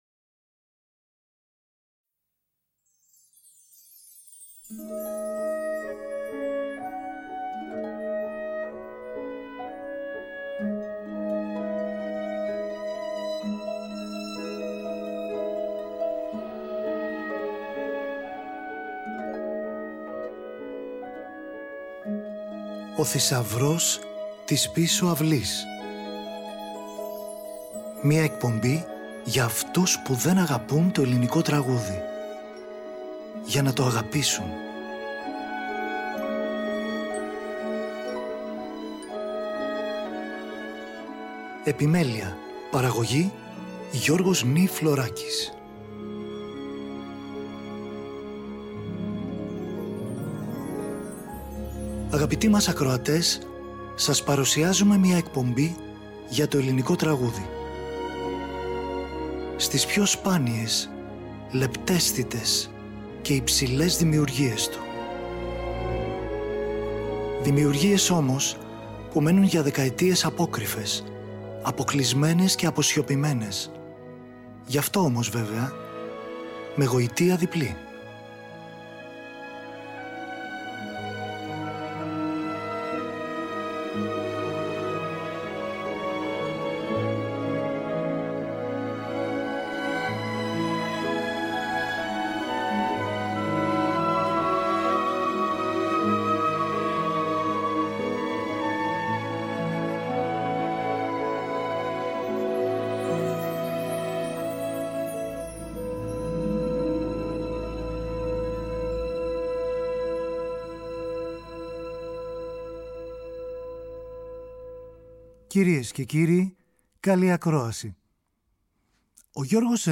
μελοποιημένη ποίησή του